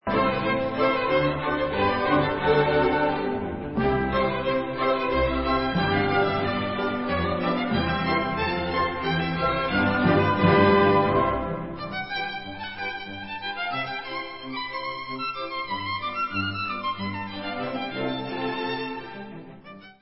housle
Koncert pro housle a orchestr C dur: